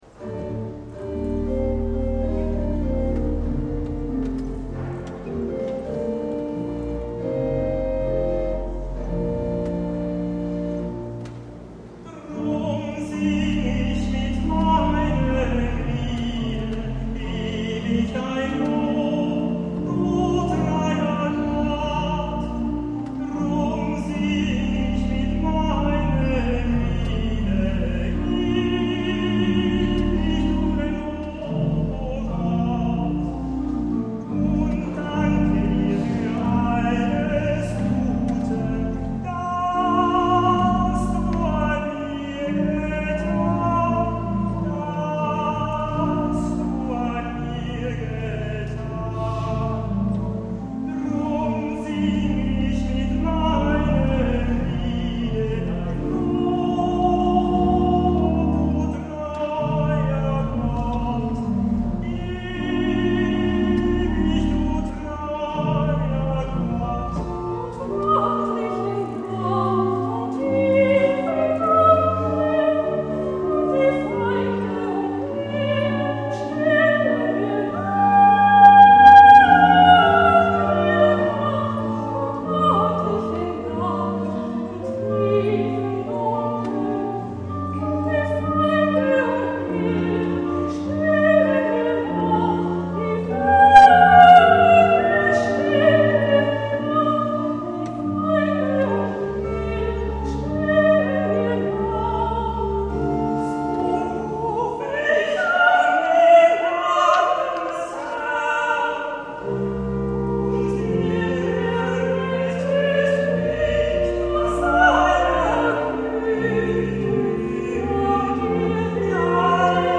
Auferstehungsmesse Ostern 2011
Sopran
Tenor